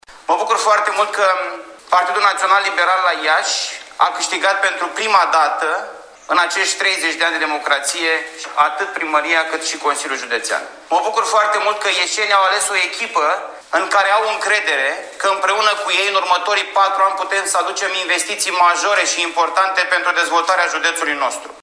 Ministrul Mediului, Costel Alexe, candidatul liberal la preşedinţia Consiliului Judeţean Iaşi, a declarat, după închiderea urnelor de vot, că PNL a câştigat la Iaşi.